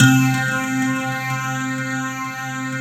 FLNGHARPC3-L.wav